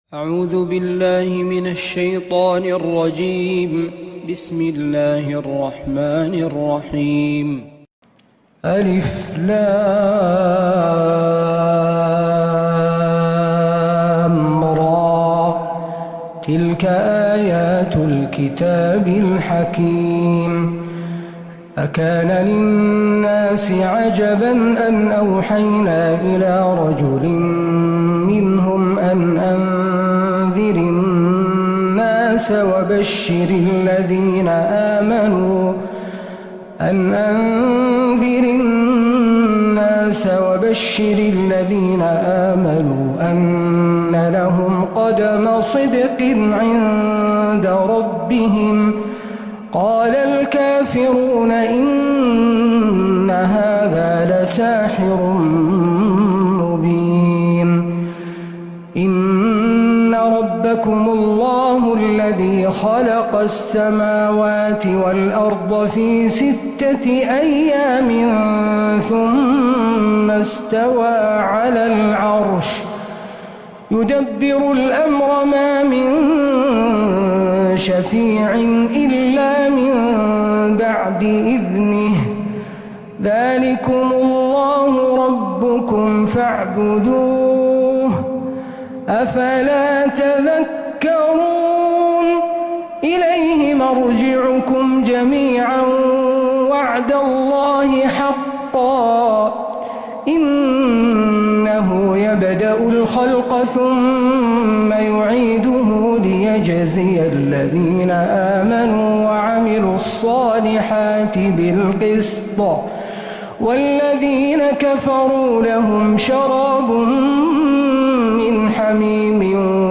من الحرم النبوي 🕌
تلاوات الحرمين